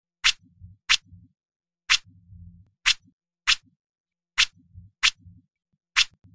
Tiếng Lướt điện thoại, Lướt web, Cuộn màn hình TikTok…
Thể loại: Tiếng đồ công nghệ
Description: Tiếng lướt điện thoại, lướt web, cuộn màn hình TikTok, tiếng vuốt màn hình, kéo newsfeed, duyệt trang, là âm thanh đặc trưng: whoosh/vút, swipe/xoẹt nhẹ...
tieng-luot-dien-thoai-luot-web-cuon-man-hinh-tiktok-www_tiengdong_com.mp3